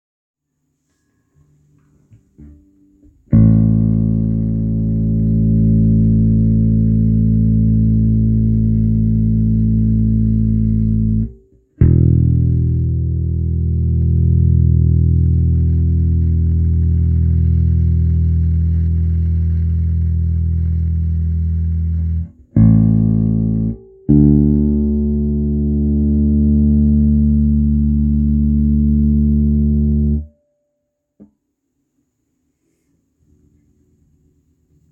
Hallo zusammen, mein Mesa D-800 schickt zwischendurch ein Rauschen auf meine Boxen.
Edit: ich hab mal eine Aufnahme vom Rauschen angehängt. Die Aufnahme wurde direkt mit dem Mic vor der Box gemacht. Man hört auch die Unterschiede in der Lautstärke des Rauschen deutlich.